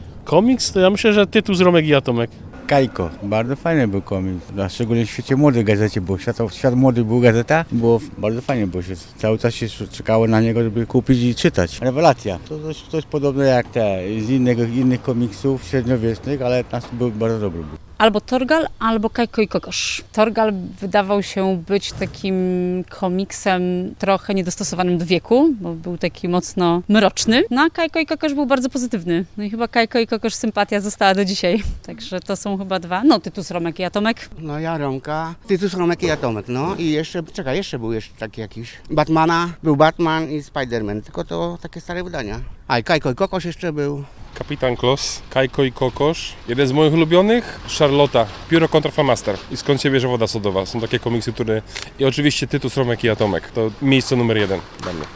Zapytaliśmy wrocławian, które komiksy ze swojego dzieciństwa wspominają najlepiej.
sonda-ulubione-komiksy-z-dziecinstwa.mp3